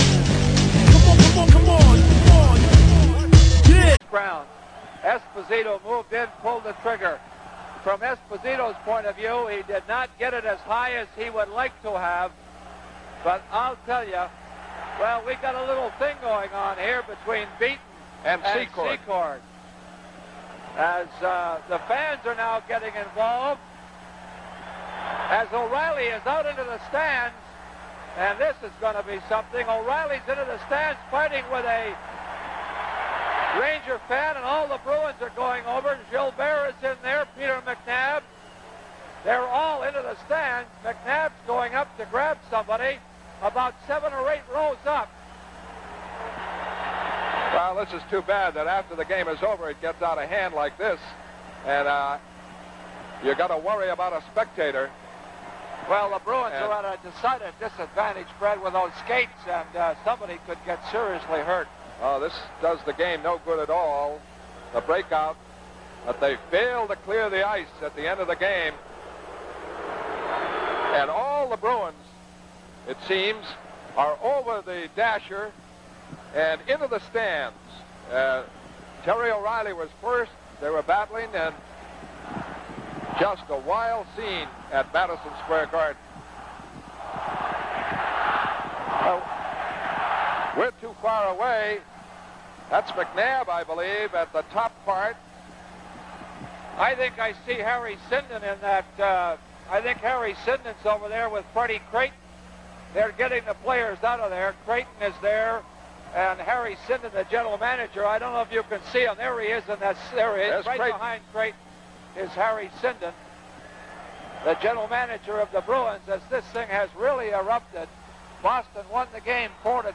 Schön das die Kommentatoren auch ihren Spaß hatten ^^
joa, kanadisches Franz ist ziemlich krass...